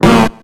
Cri de Ronflex dans Pokémon X et Y.